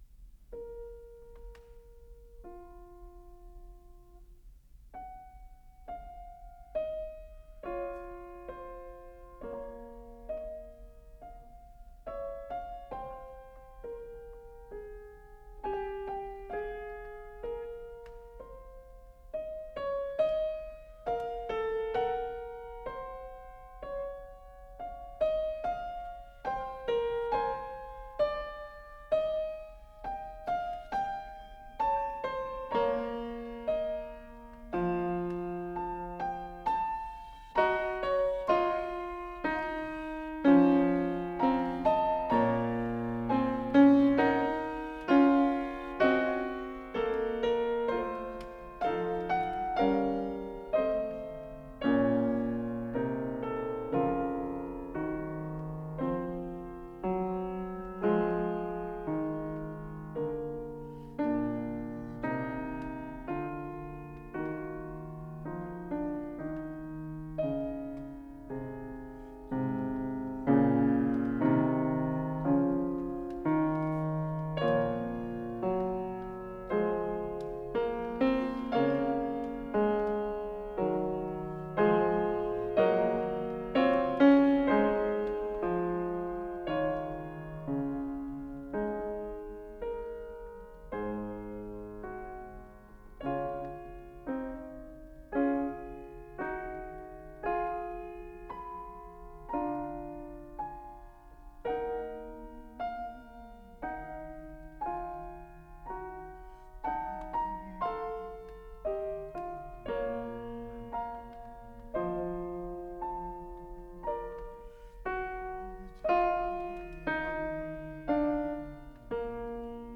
12 Wtc Vol I, Fugue No 22 In B Flat Minor, Bwv867